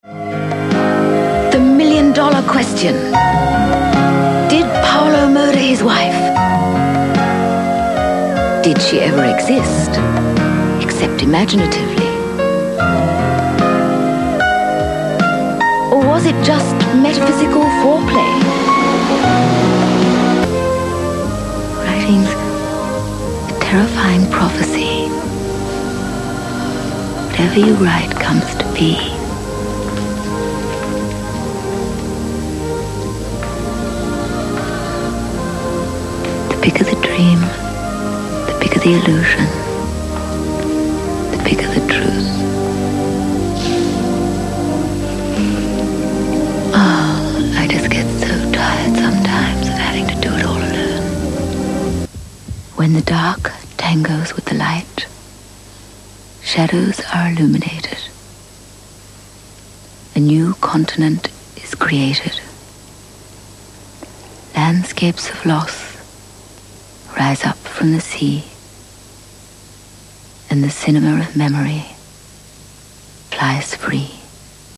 englische (uk) Sprecherin, Muttersprache.
Sprechprobe: Werbung (Muttersprache):